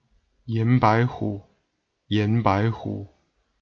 Standard Mandarin
Hanyu PinyinYán Báihǔ